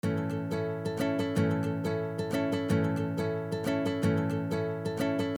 دقت فرمایید که این پکیج گیتار، وی اس تی و یا ساز مجازی نمی باشد و تمام ریتم ها، آرپژ ها و موارد دیگر در استودیو با بالاترین کیفیت رکورد شده اند!
ریتم شیش و هشت بندری سنتر (بستکی)
6.8-3-bandari-c.mp3